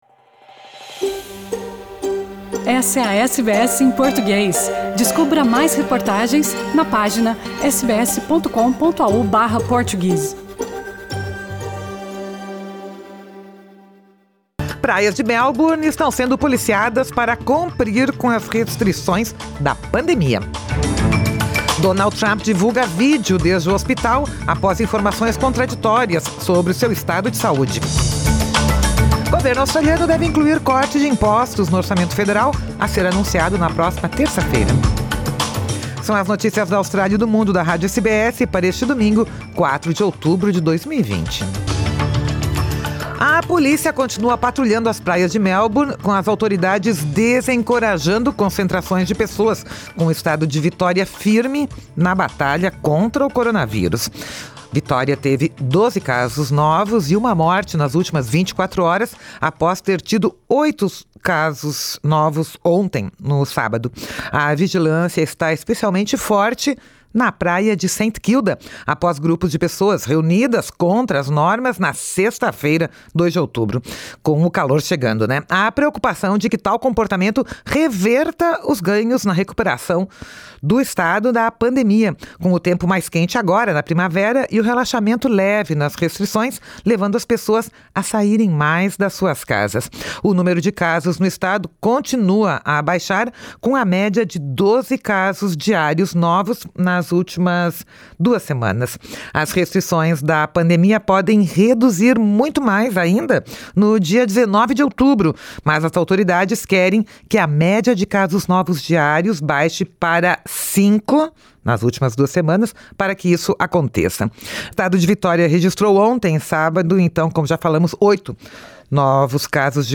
São as notícias da Austrália e do Mundo da Rádio SBS para este domingo, 4 de outubro de 2020